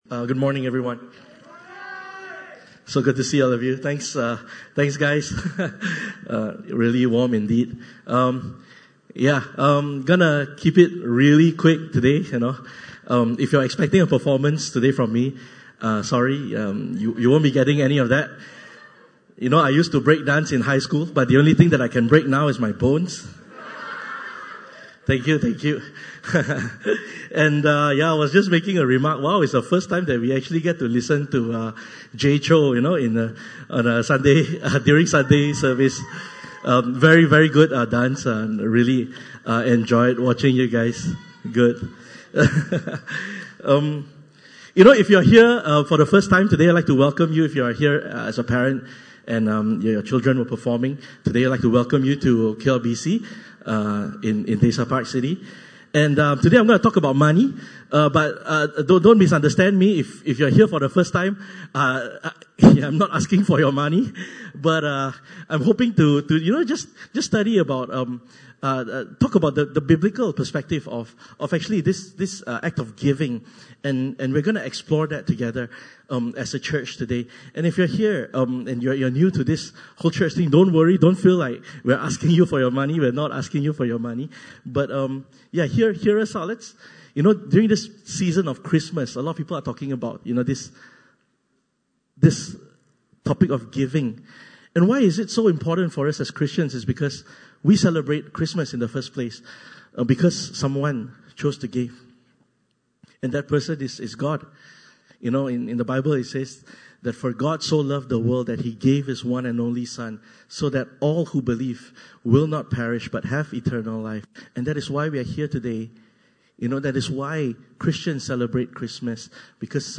Passage: 2 Corinthians 9:6-8 Service Type: Sunday Service (Desa ParkCity) « Storms Ahead!